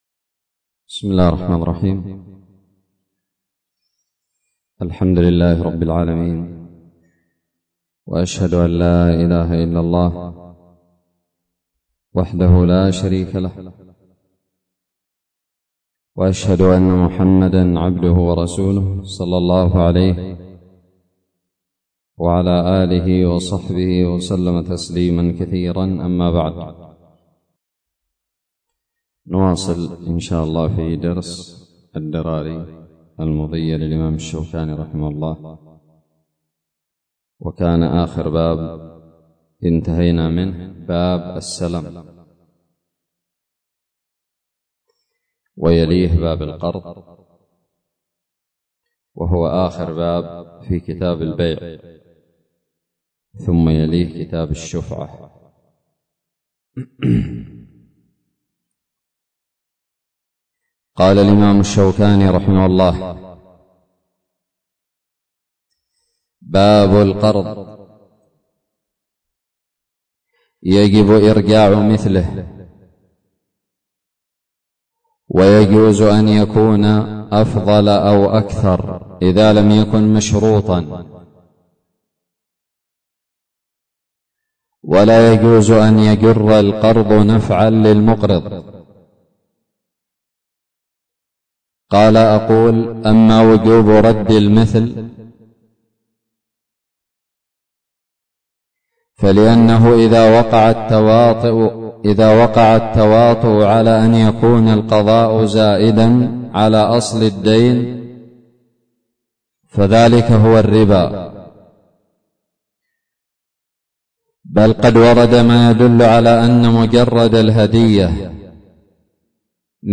الدرس السادس والثمانون من كتاب البيع من الدراري
ألقيت بدار الحديث السلفية للعلوم الشرعية بالضالع